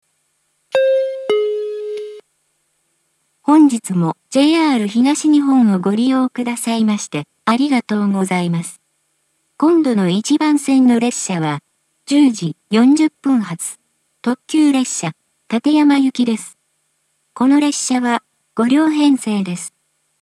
sanukimachi-1bannsenn-yokoku.mp3